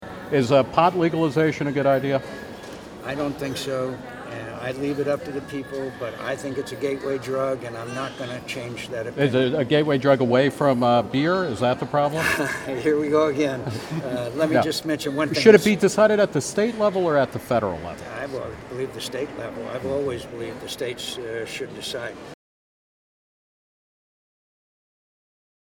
Today outside the Values Voters Summit in Washington, D.C., Reason TV caught up with Sen. John McCain (R-Az.) and asked him his thoughts on marijuana legalization.